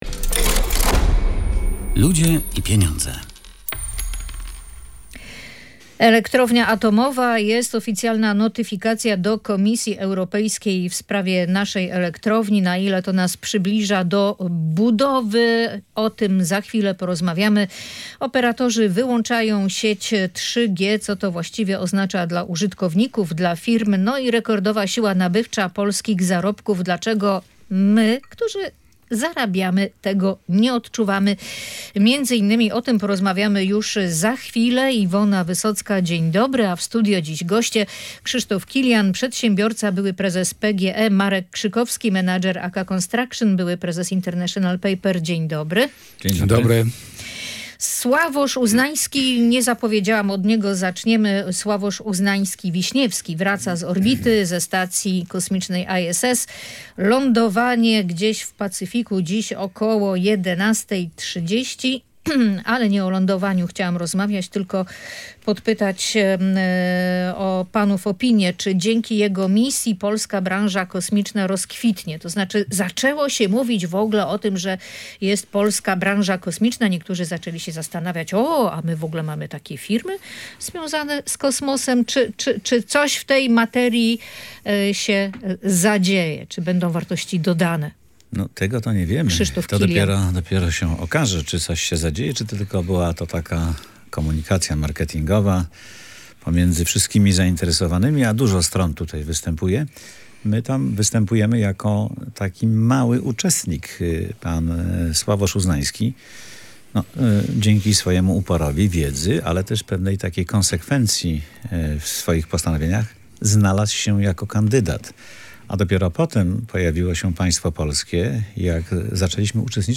Były prezes PGE Krzysztof Kilian, który był gościem audycji „Ludzie i Pieniądze”, obawia się, że nie przyspieszy to samego procesu budowy.